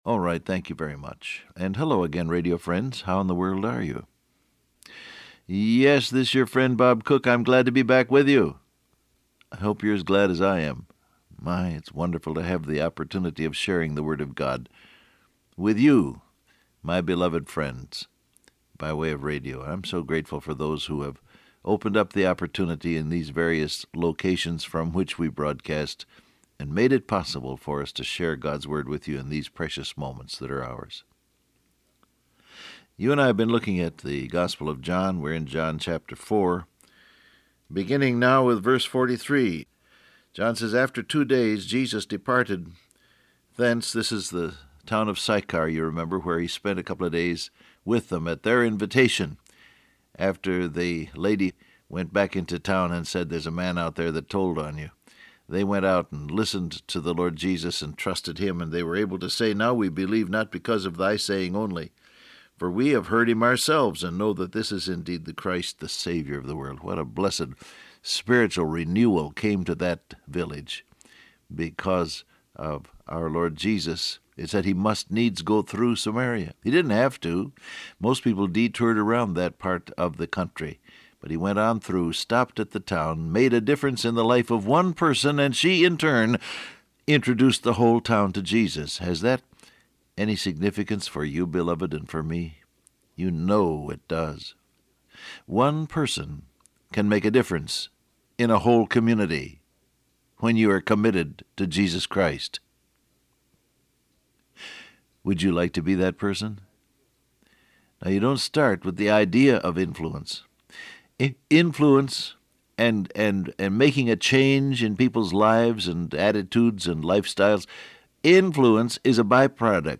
Download Audio Print Broadcast #6837 Scripture: John 4:43-45 Topics: Witnessing , Revival , God Gives His Best Transcript Facebook Twitter WhatsApp Alright, thank you very much.